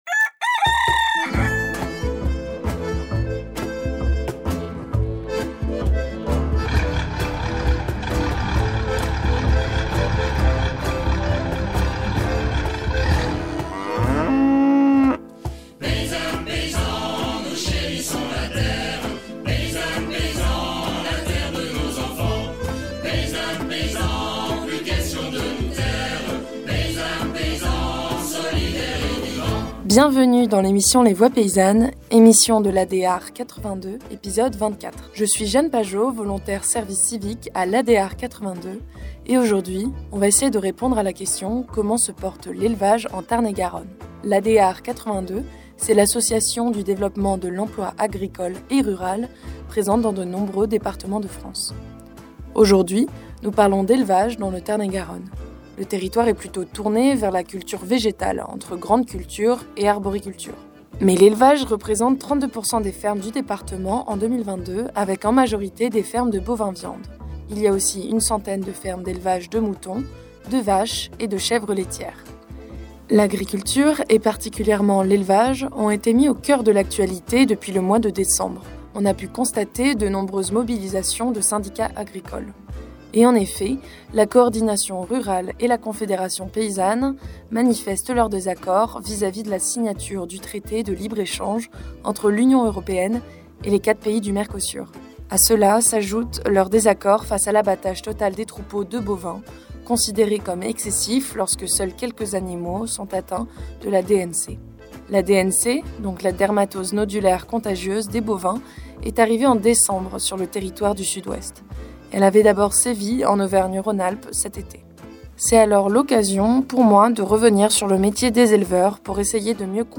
Émissions